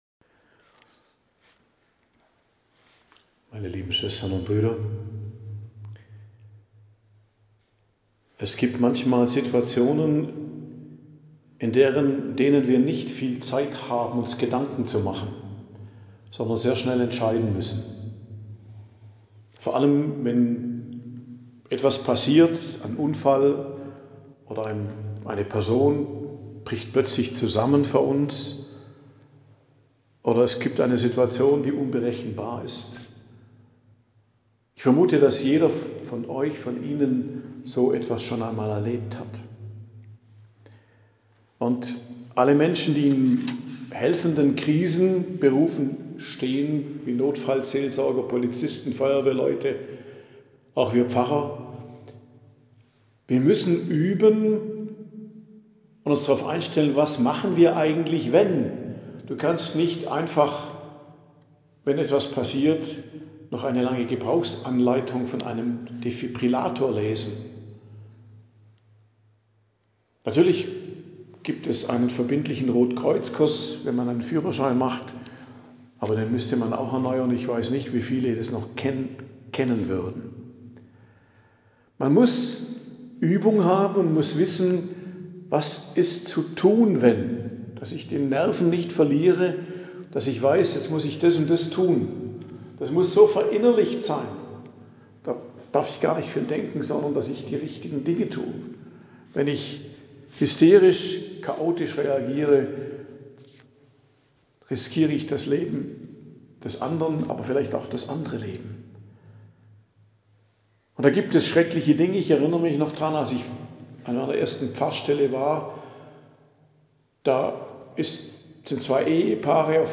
Predigt am Dienstag der 13. Woche i.J., 1.07.2025 ~ Geistliches Zentrum Kloster Heiligkreuztal Podcast